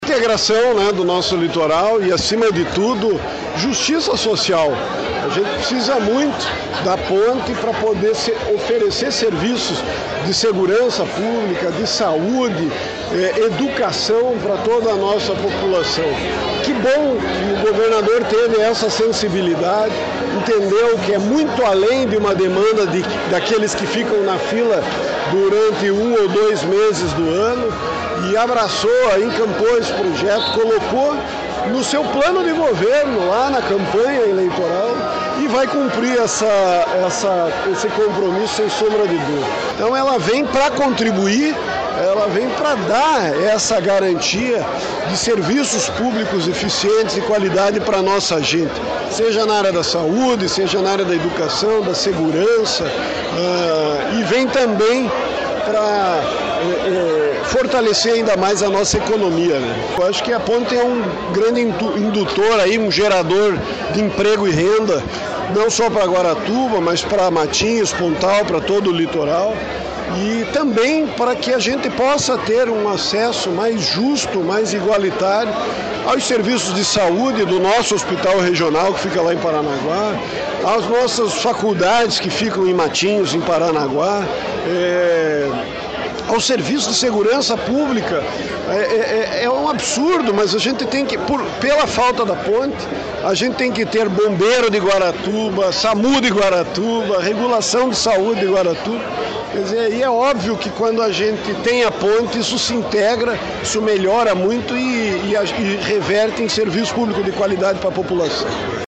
Sonora do prefeito de Guaratuba, Roberto Justus, sobre o início da obra da Ponte de Guaratuba